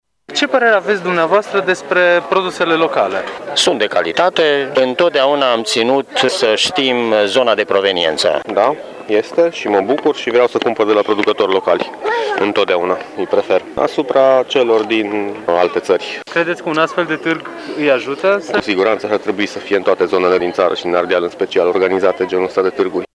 Mureșenii s-au arătat interesați de produsele locale și suțin că atât calitatea cât și zona de proveneință a produselor sunt factori importanți în momentul achiziției: